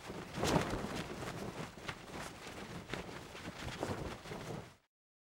cloth_sail16.R.wav